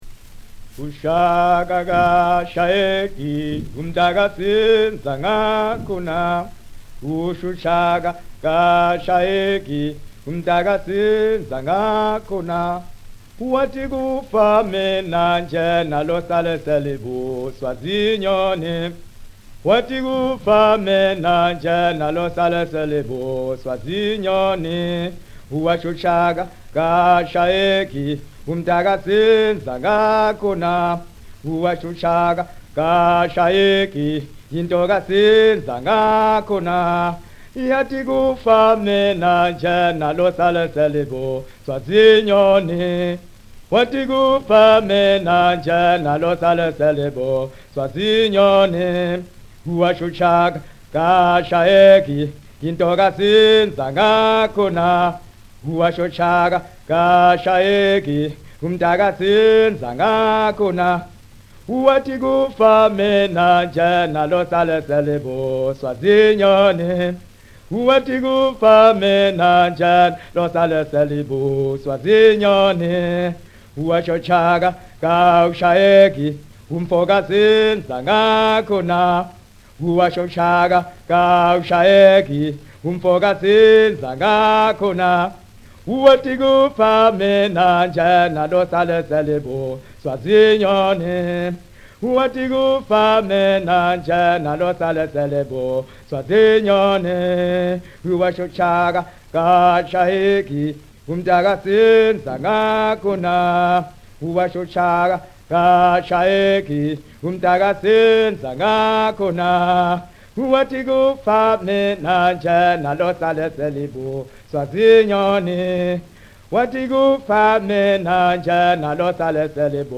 (in Zulu)
78 rpm
mono
(traditional)
vocal, clapping